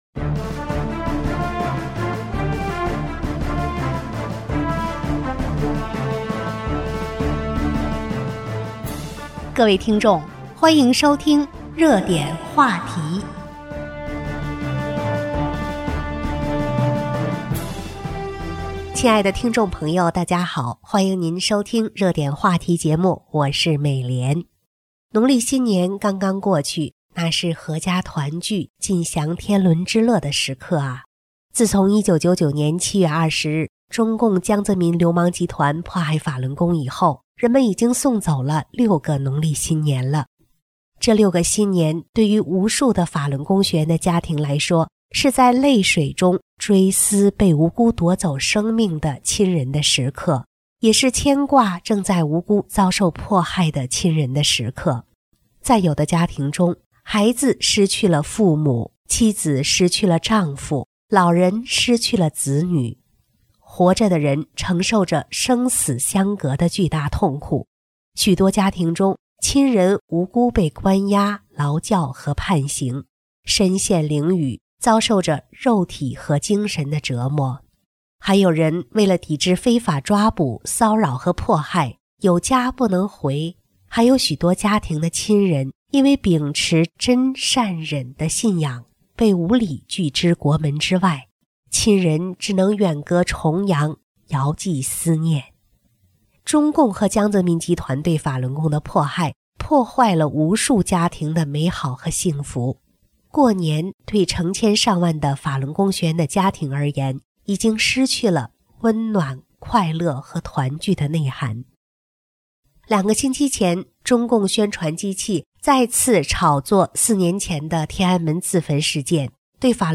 广播录音：谁在延续罪恶? -- 追查迫害法轮功的舆论打手
这里提供下载的MP3文件声音质量比RM文件好 农历新年刚刚过去，那是阖家团聚，尽享天伦之乐的时刻啊。